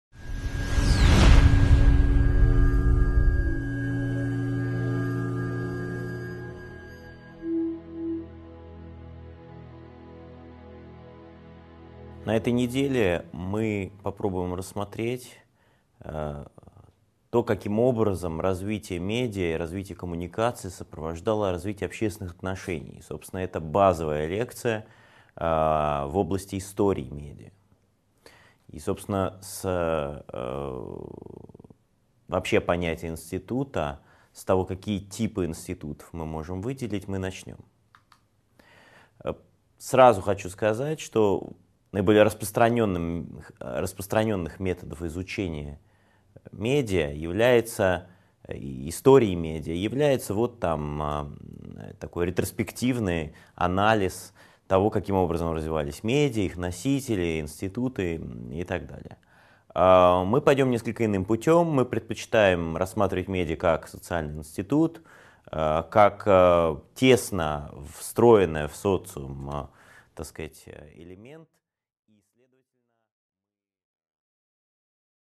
Аудиокнига 2.1 Медиа и институты | Библиотека аудиокниг